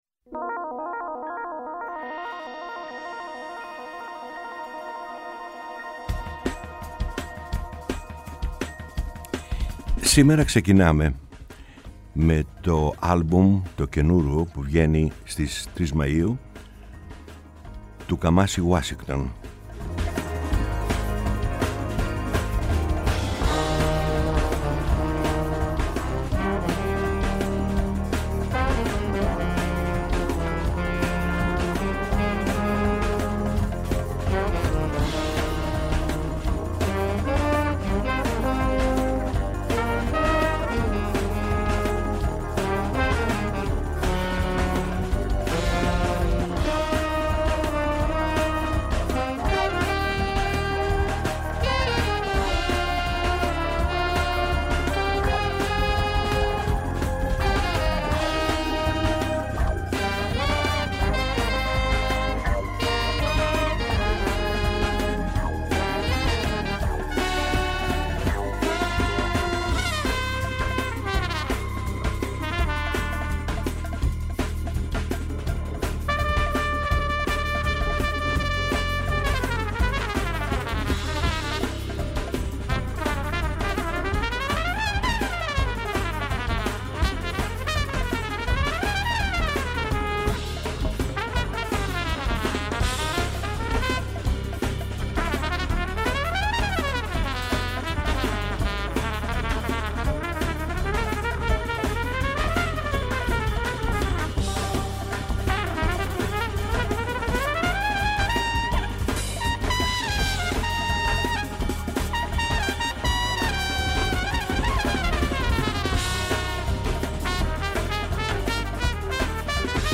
Η μακροβιότερη εκπομπή στο Ελληνικό Ραδιόφωνο!